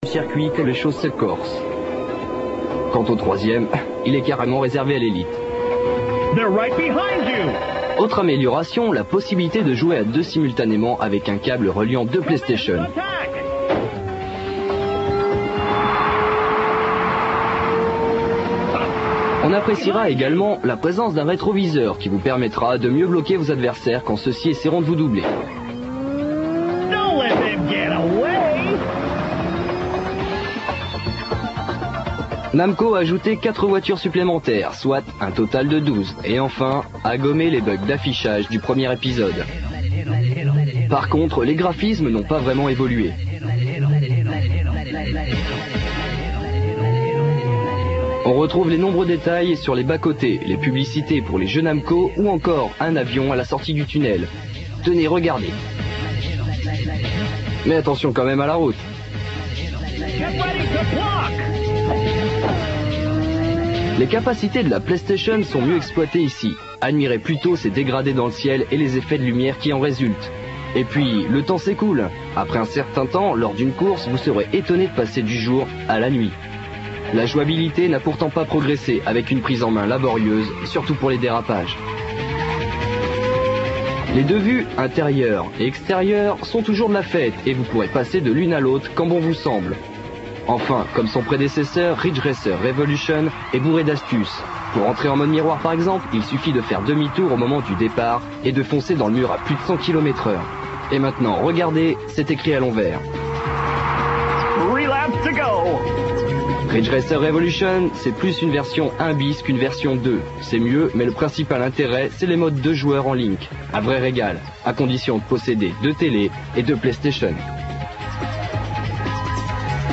La qualité de la vidéo en streaming Real Multimedia est volontairement dégradée afin qu'elle soit rapidement téléchargée et affichée.